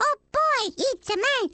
One of Baby Mario's voice clips from the Awards Ceremony in Mario Kart: Double Dash!!
Source Ripped Artist Charles Martinet Image help • Image use policy • Media file guidelines Licensing [ edit ] Fair use sound clip This is a sound clip from a copyrighted work.